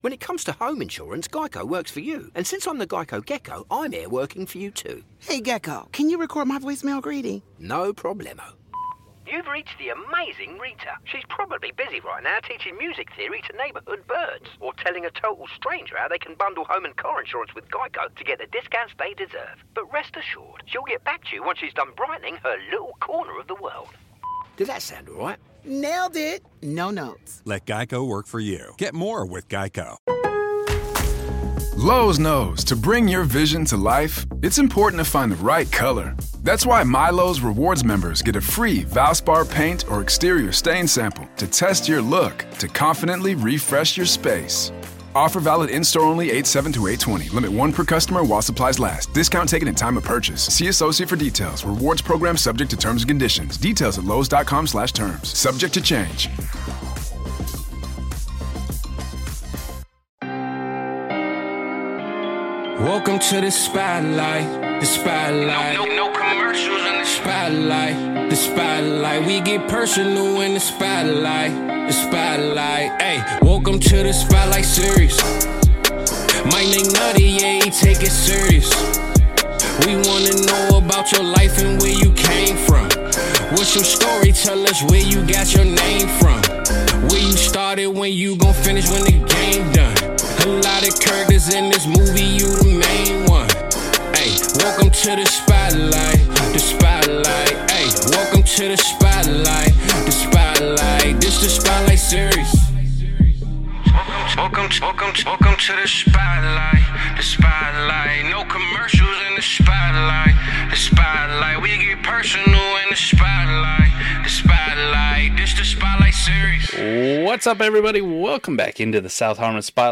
Tune in for a candid conversation full of humor, brotherly banter, and some of the best advice you’ll hear in the fantasy football community.